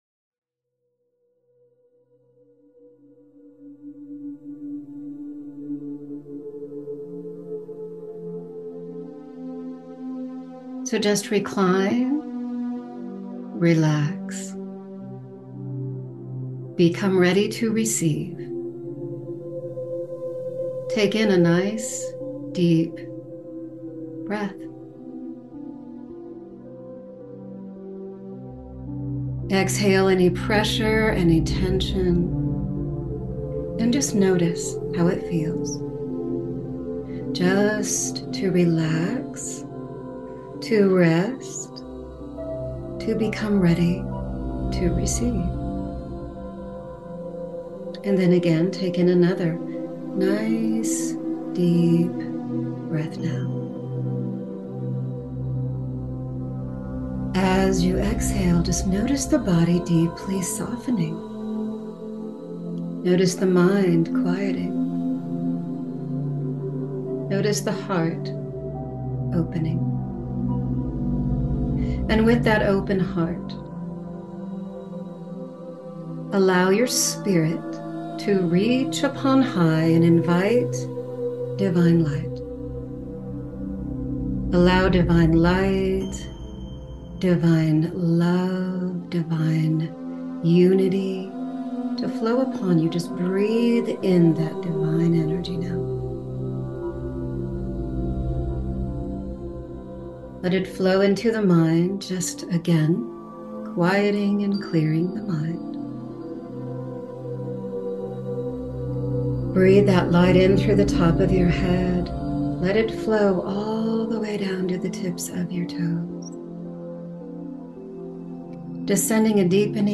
Guided Meditation with hypnosis to open your spirit to allow and receive joy, happiness, and elation into every cell!